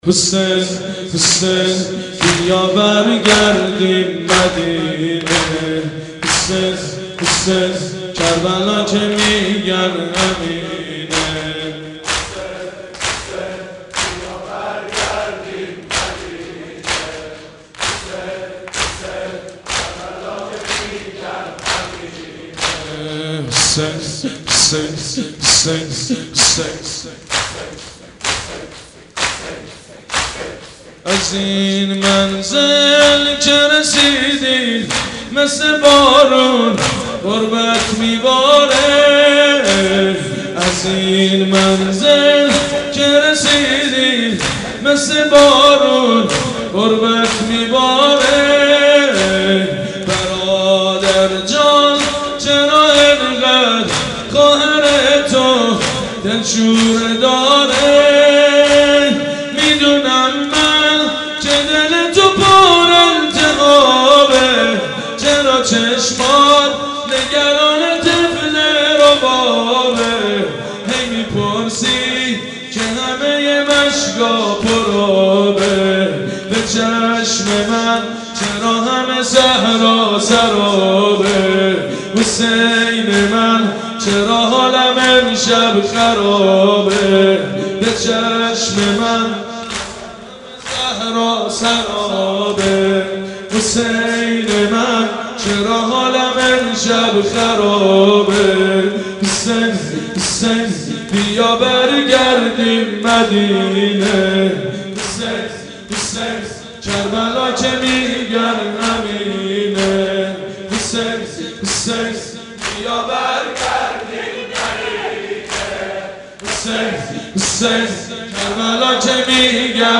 شب دوم محرم